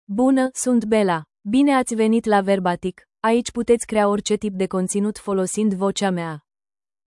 Bella — Female Romanian (Romania) AI Voice | TTS, Voice Cloning & Video | Verbatik AI
BellaFemale Romanian AI voice
Voice sample
Listen to Bella's female Romanian voice.
Female